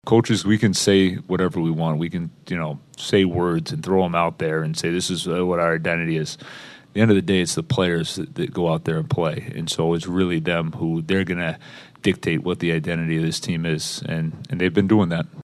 Muse credits the players for building a team identity as a group that plays hard on both ends of the ice.